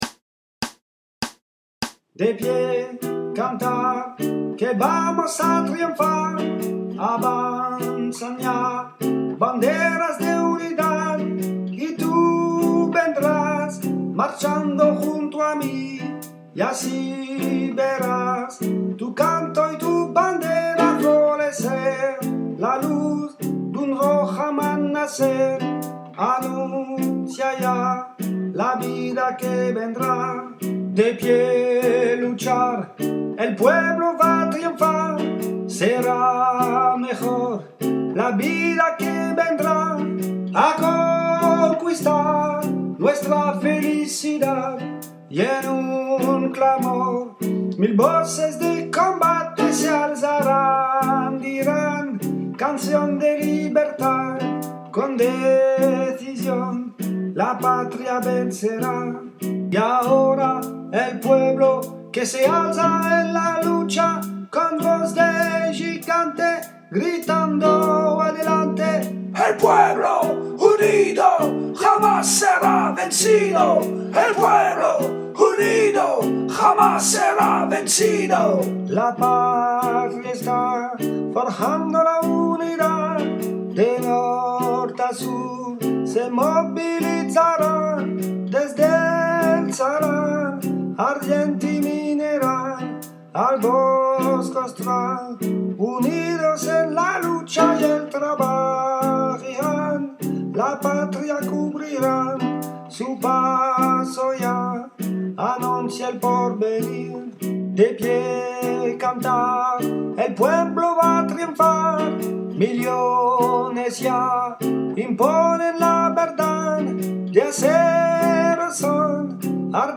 –> Version 3 voix de la chorale de l’Usine de la Redonne : lead (haute)